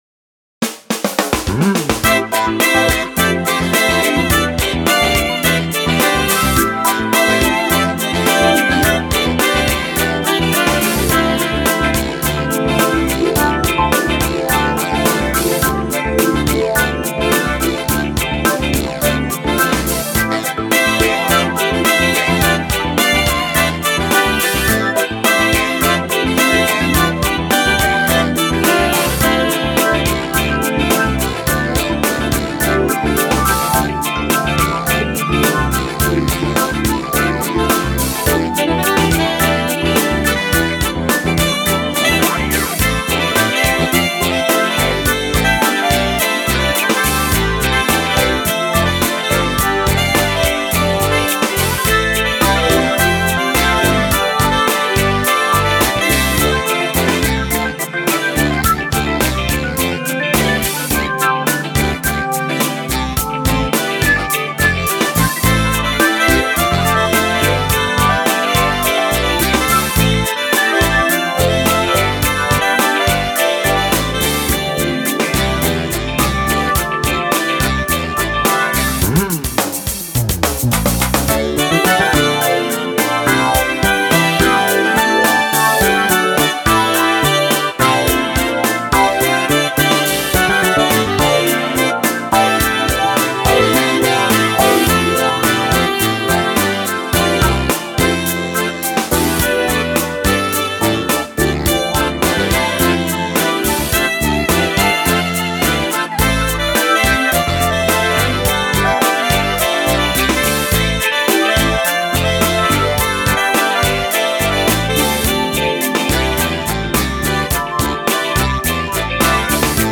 Genre : Contemporary Christian
Keyboards, Accordion, Guitar, Ukulele, Bass Guitar, Drums
Trumpet, Flumpet and all Brass Instruments